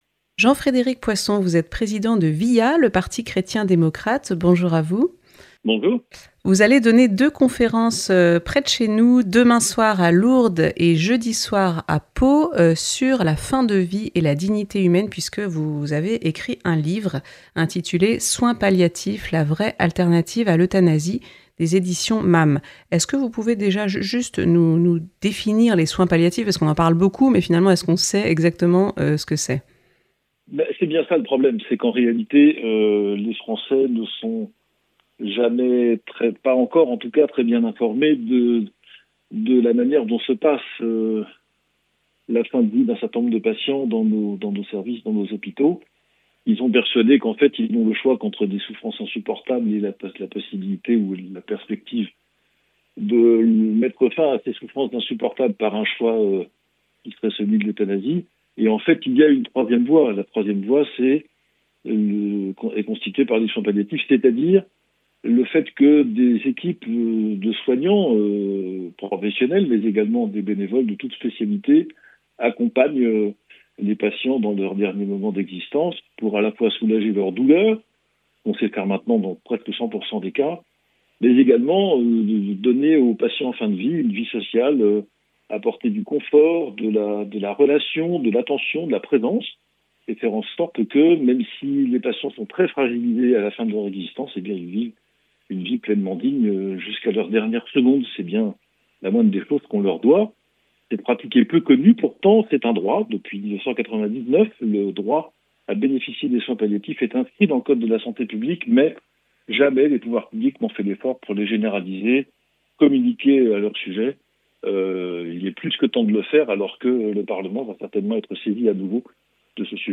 Jean-Frédéric Poisson, président de VIA, le parti chrétien démocrate, nous parle de l’importance des soins palliatifs, une vraie alternative à l’euthanasie.